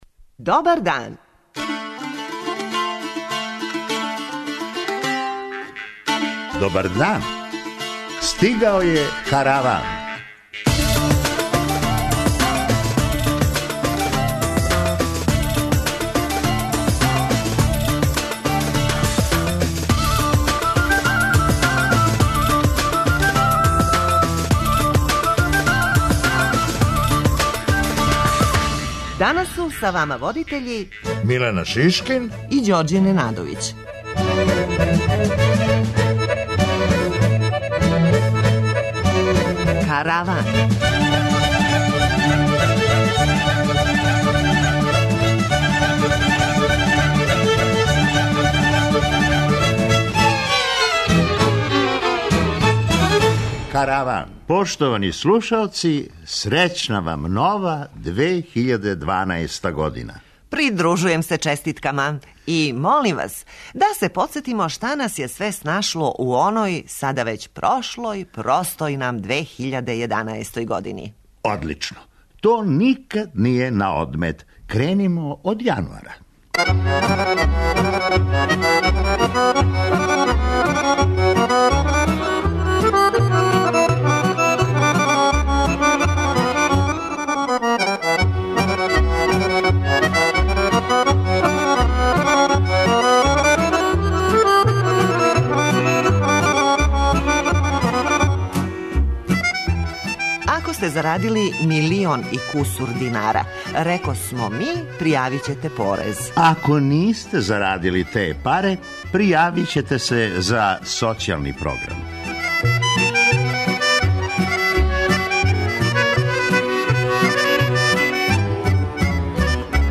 Инсерти из наших емисија, моменти за које сматрамо да су заслужили да буду репризирани.
преузми : 23.45 MB Караван Autor: Забавна редакција Радио Бeограда 1 Караван се креће ка својој дестинацији већ више од 50 година, увек добро натоварен актуелним хумором и изворним народним песмама.